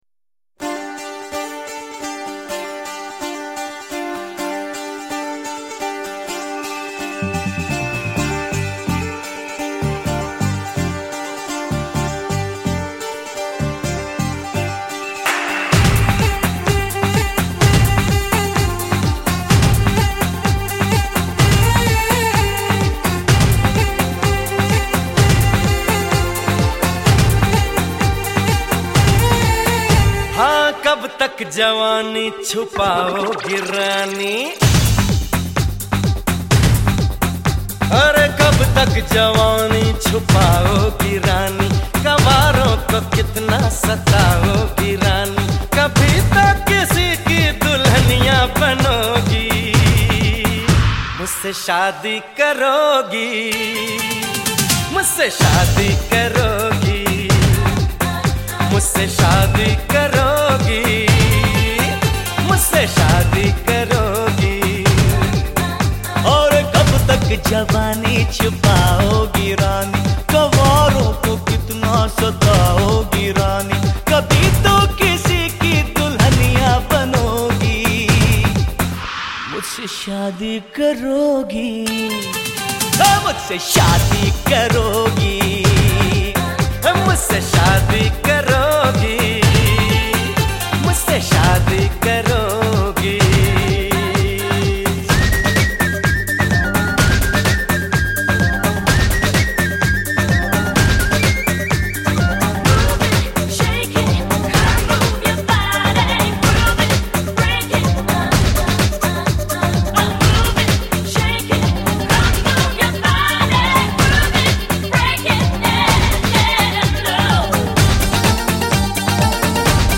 Home » Bollywood Mp3 Songs » Bollywood Movies